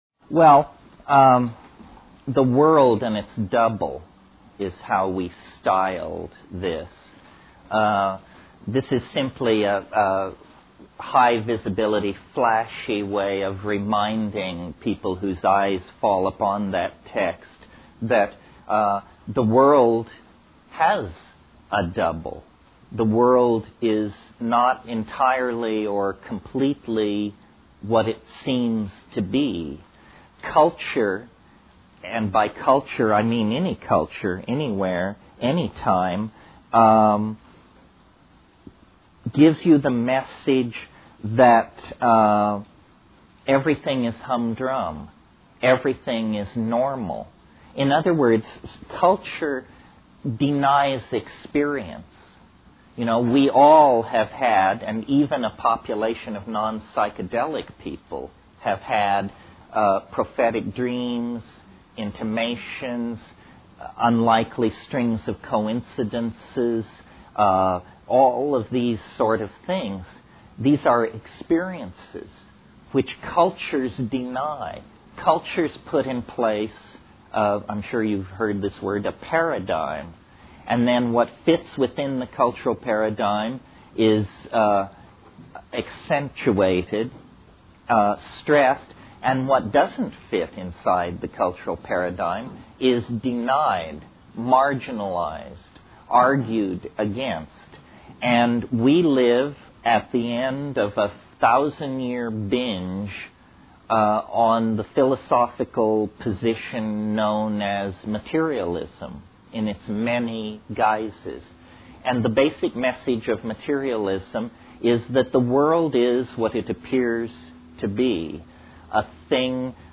Terence McKenna was of the latter 20th century; he did what might be called performative speculation (group talks, workshops, lectures and the like).
At any given time he had full command of pop, arcane, and literate culture, forging wild combinations with his special nasal drawl.
Listen: The World and its Double , lecture, 1993